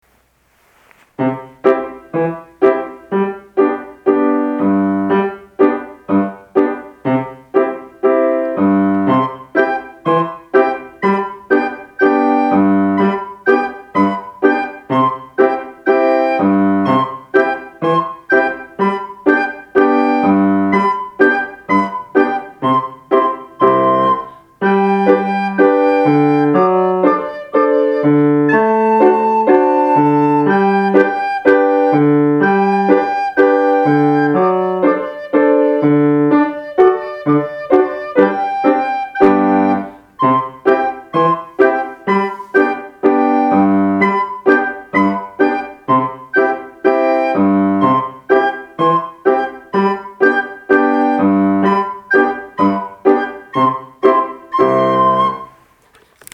リコーダー(４年生)
楽しい曲なので休みの間に是非チャレンジしてみてください。